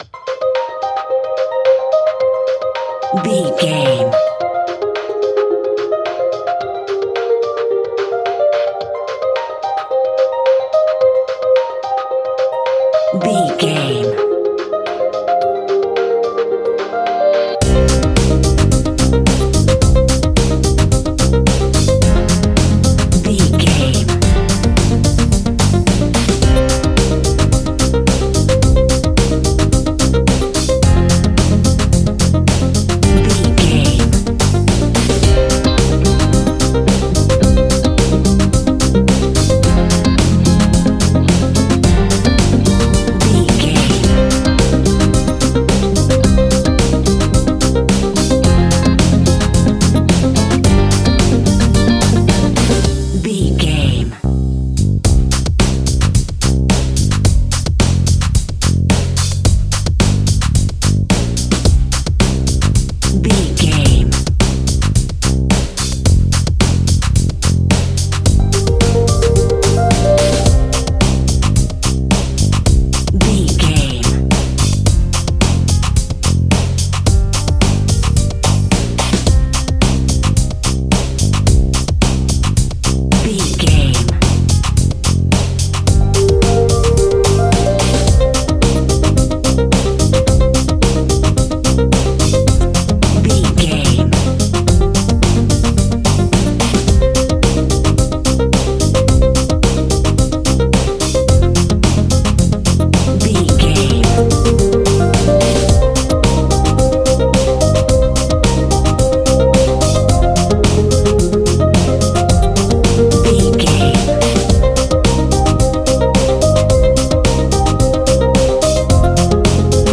Aeolian/Minor
groovy
funky
lively
electric guitar
electric organ
drums
bass guitar
saxophone
percussion